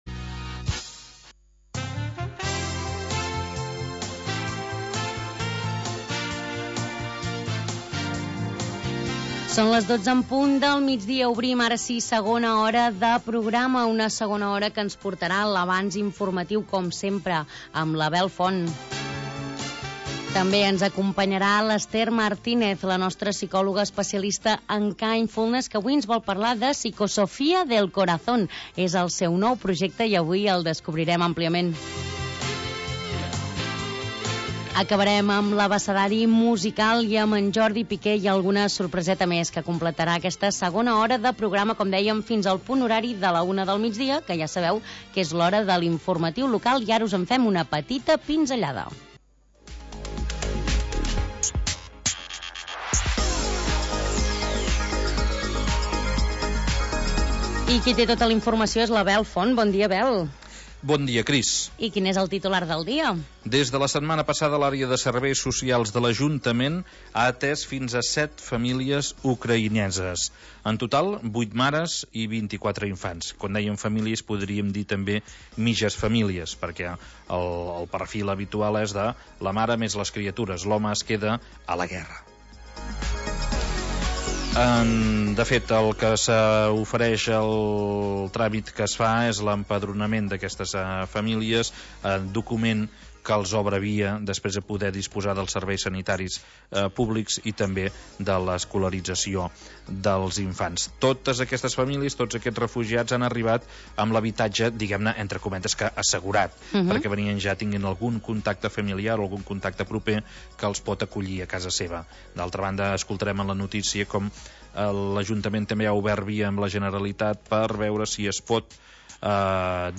Un magazín d'entreteniment en temps de confinament.